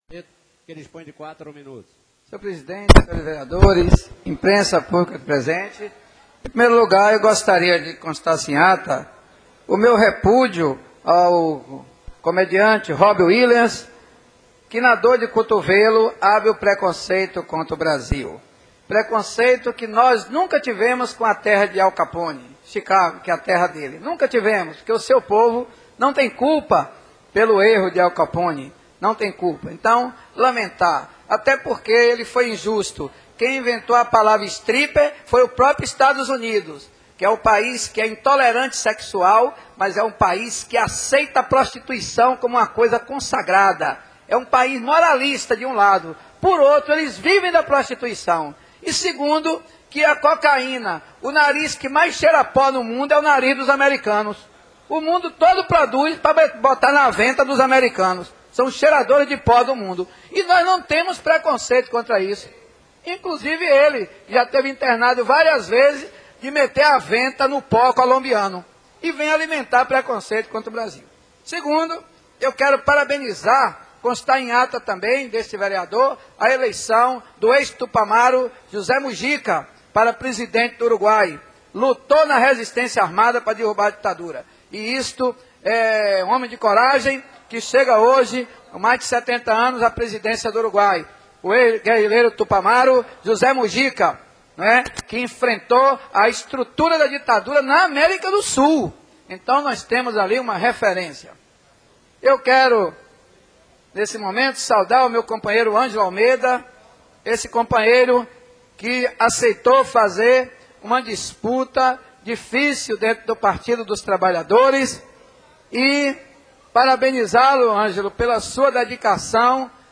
Marialvo Barreto (PT) usa a tribuna da Câmara para falar sobre a infeliz declaração do ator americano Robin Williams e comenta sobre o PED do PT.